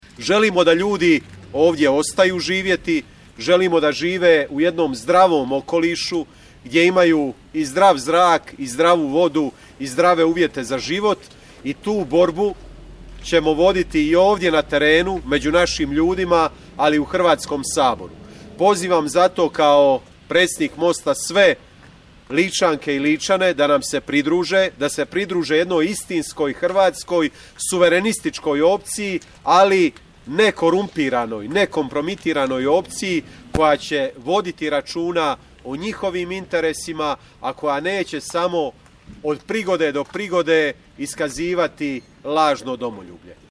Ispred novoizgrađene tvornice za obradu litija u poslovnoj zoni Smiljansko polje u Gospiću danas je održana press konferencija Mosta, na kojoj je upozoreno na nedostatak transparentnosti projekta i moguće posljedice po okoliš i zdravlje građana. Predsjednik Mosta i saborski zastupnik Nikola Grmoja istaknuo je kako će se protiv ovakvih projekata boriti i na terenu i u Hrvatskom saboru.